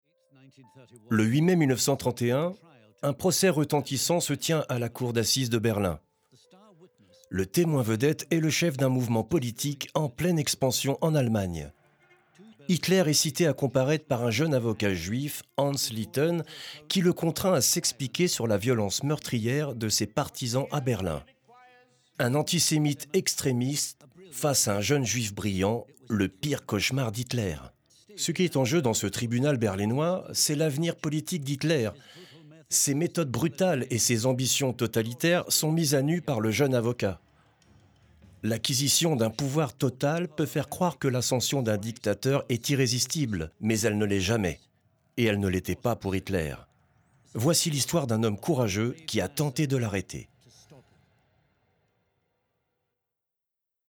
Documentaire / Narration / Voice Over
Voix narration – Documentaire – Hitler aux assises